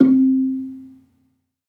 HSS-Gamelan-1 / Gambang
Gambang-C3-f.wav